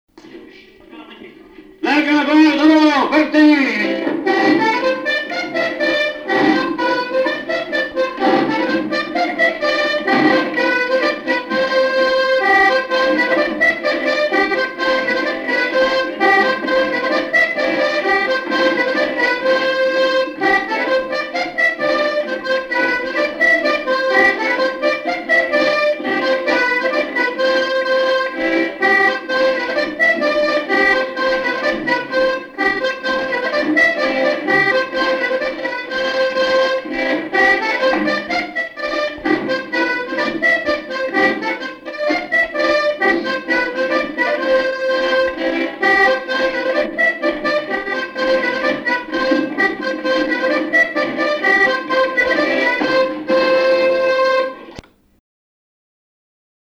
Bournezeau
Chants brefs - A danser
Répertoire du violoneux
Pièce musicale inédite